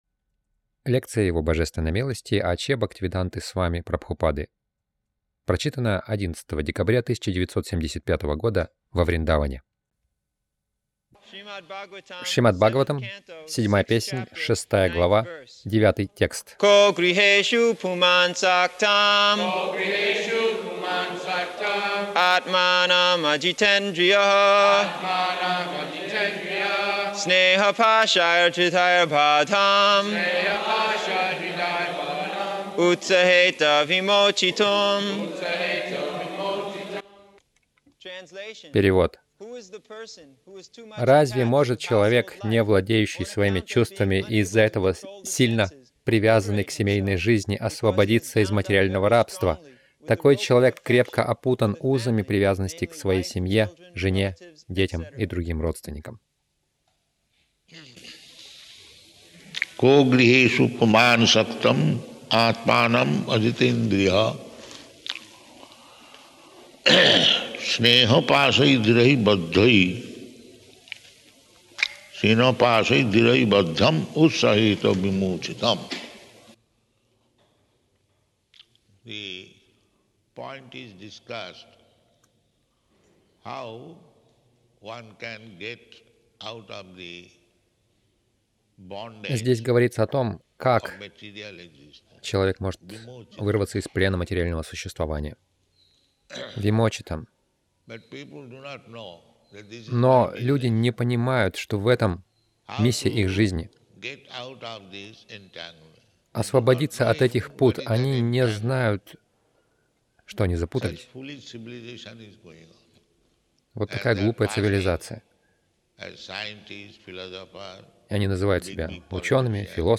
Милость Прабхупады Аудиолекции и книги 11.12.1975 Шримад Бхагаватам | Вриндаван ШБ 07.06.09 — Об отречении Загрузка...